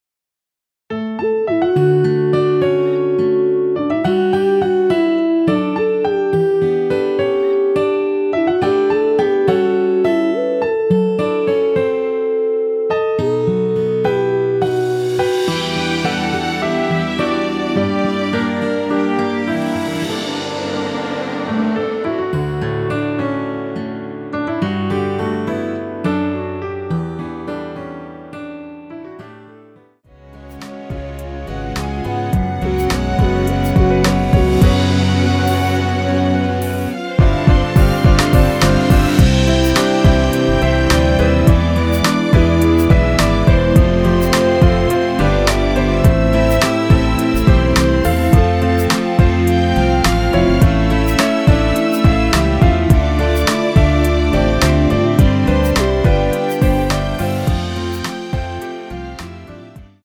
원키에서(-1) 내린 멜로디 포함된 MR 입니다.(미리듣기 참조)
앞부분30초, 뒷부분30초씩 편집해서 올려 드리고 있습니다.
곡명 옆 (-1)은 반음 내림, (+1)은 반음 올림 입니다.
(멜로디 MR)은 가이드 멜로디가 포함된 MR 입니다.